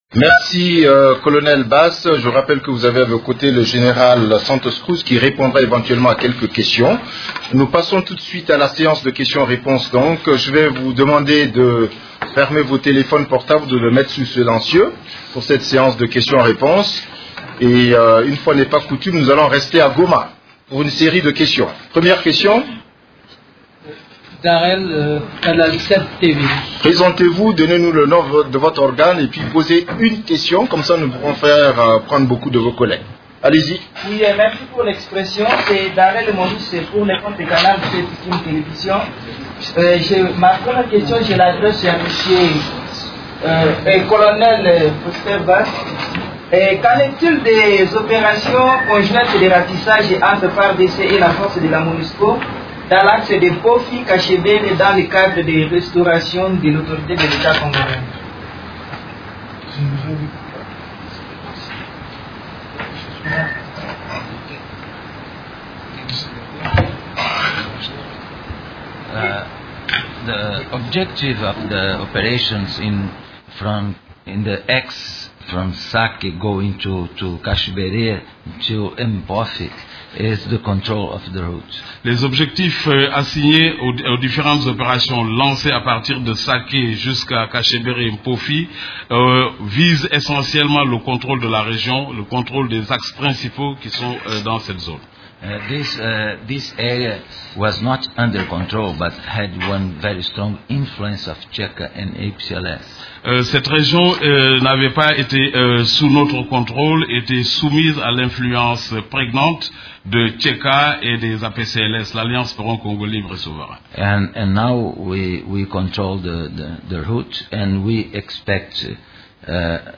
Conférence de presse du 16 juillet 2014
La conférence hebdomadaire des Nations unies du mercredi 16 juillet à Kinshasa a abordé les sujets suivants: